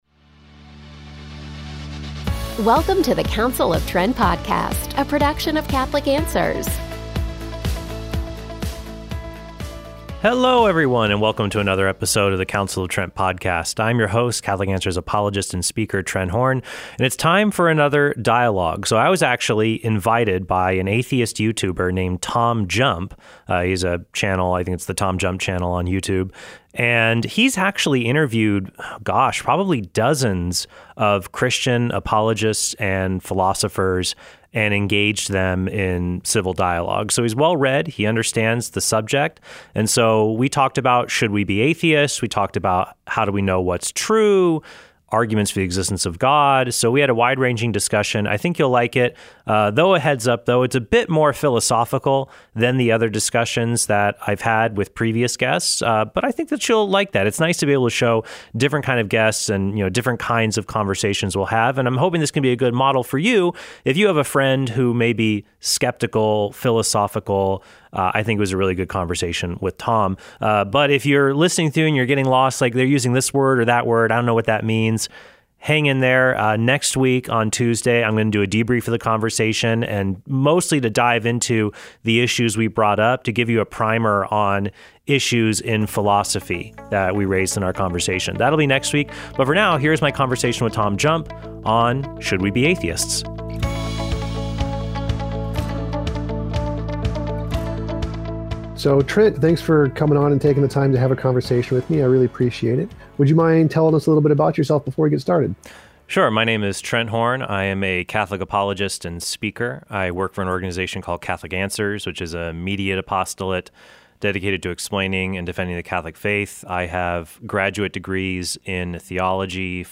We talked about, how do we know what’s true, arguments for the existence of God. So we had a wide ranging discussion.